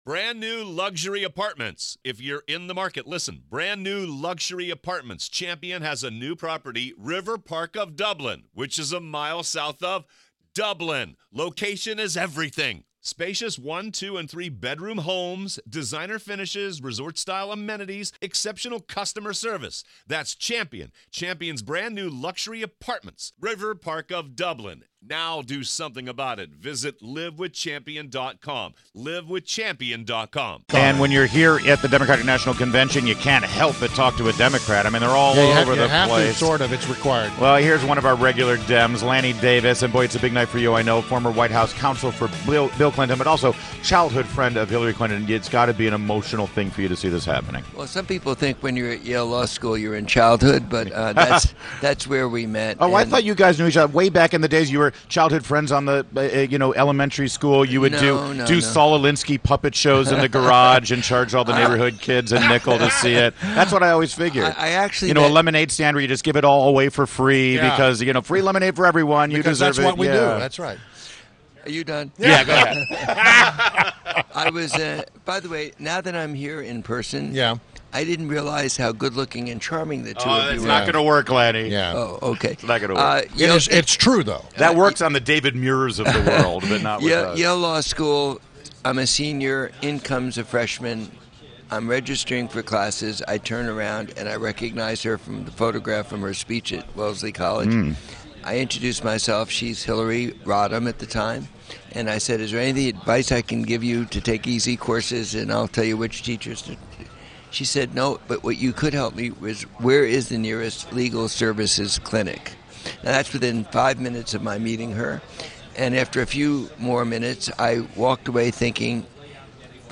WMAL Interview - Lanny Davis - 07.28.16